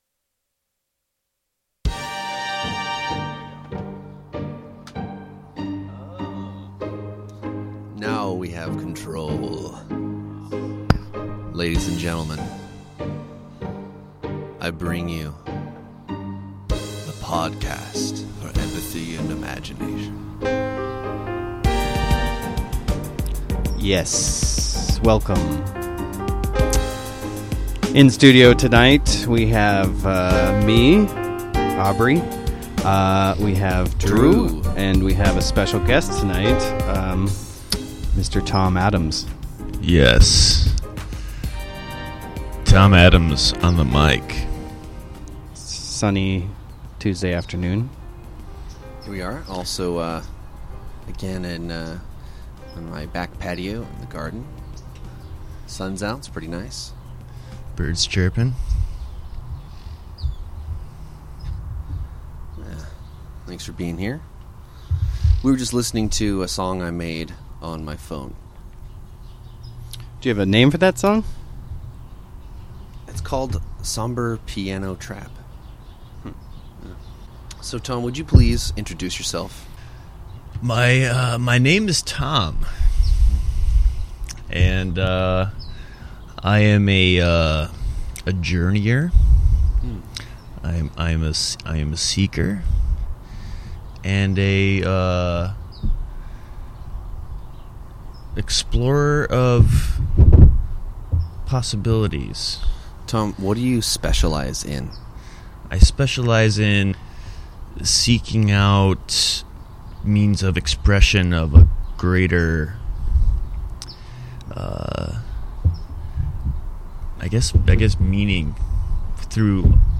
Conversations are not like magnetic levitation, in that they often derail themselves, as we demonstrate.